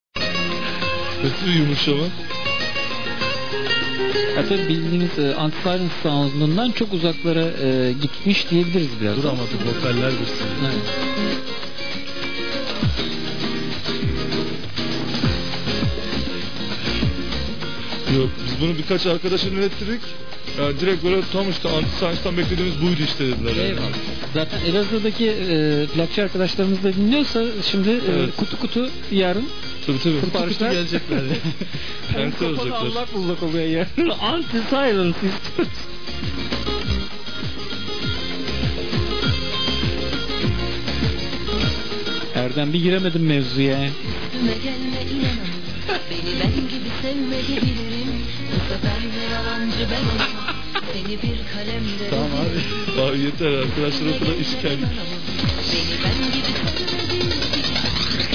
20 Kb/s - 11 Khz - Mono